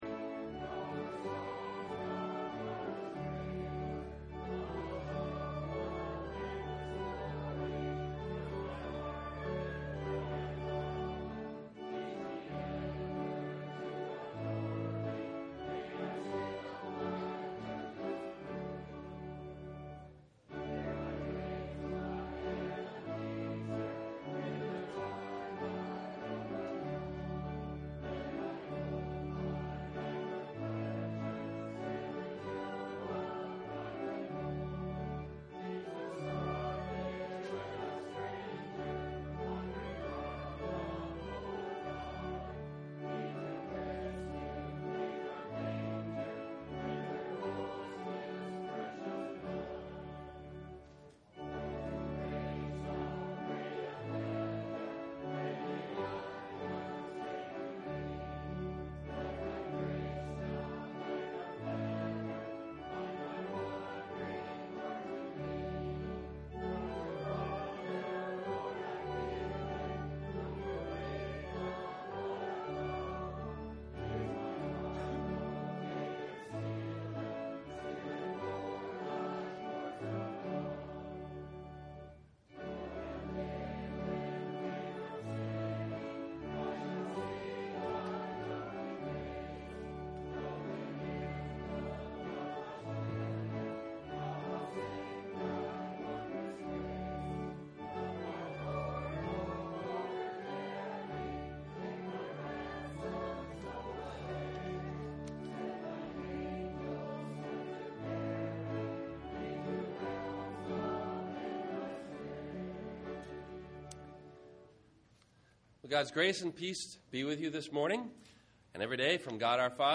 Jars of Clay – Sermon – September 05 2010